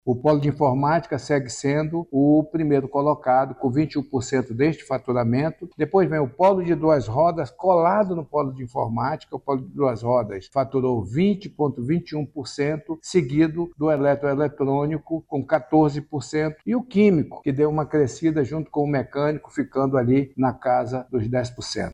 O superintendente da Zona Franca de Manaus, Bosco Saraiva, destaca os segmentos da indústria amazonense que tiveram melhores desempenhos no período.